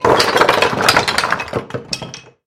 Звуки падения по лестнице
Деревянные бруски отправляем туда же